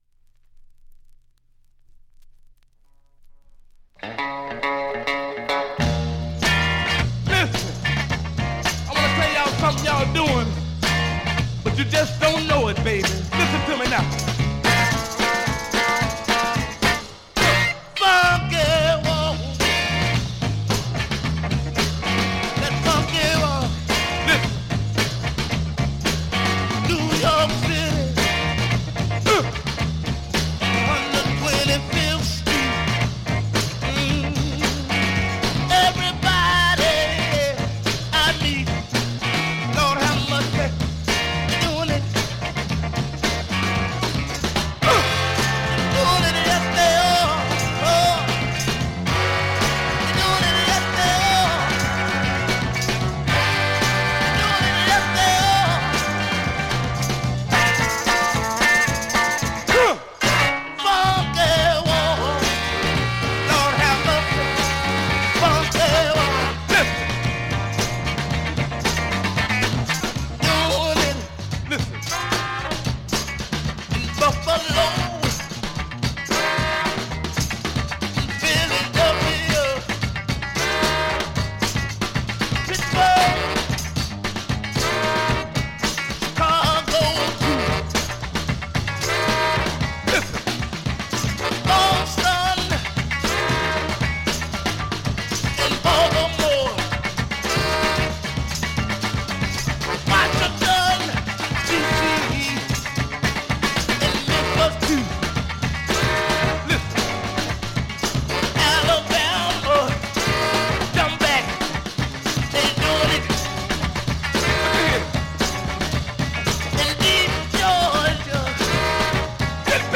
SOUL、FUNK、JAZZのオリジナルアナログ盤専門店